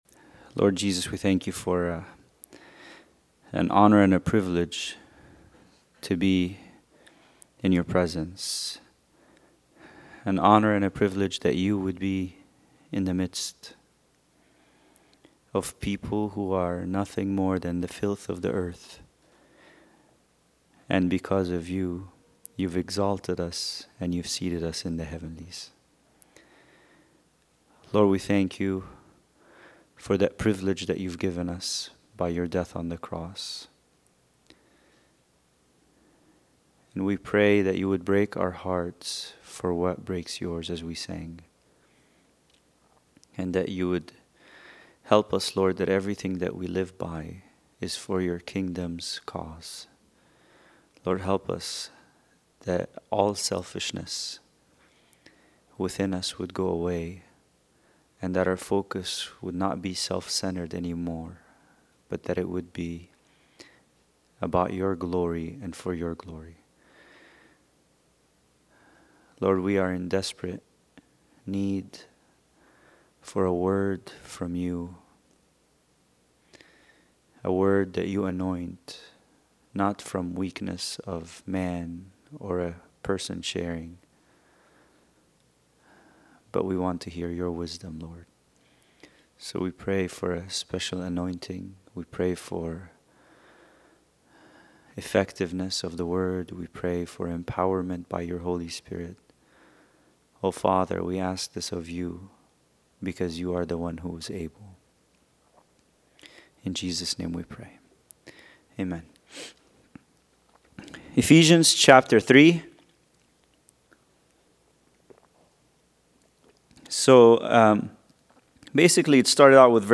Bible Study: Ephesians 3:17